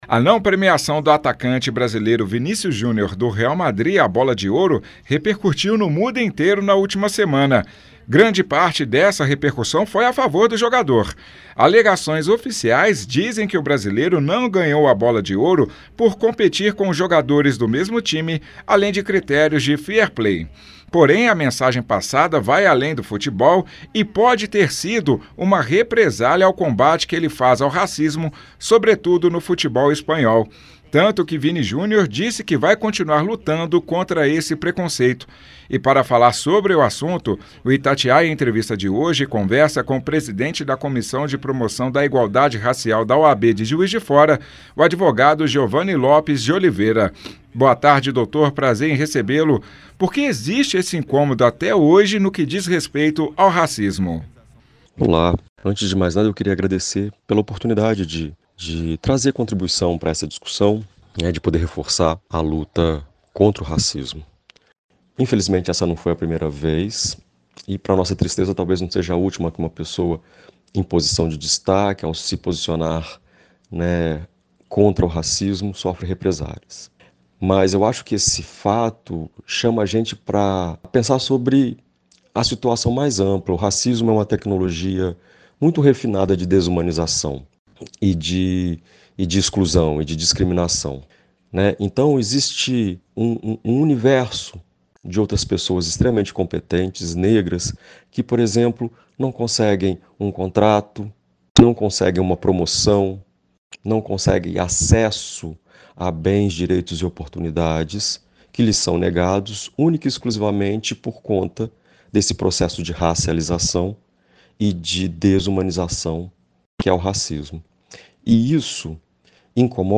04.11_Itatiaia-Entrevista-Vini-Jr-Racismo-BRUTO-1.mp3